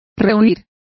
Complete with pronunciation of the translation of shunning.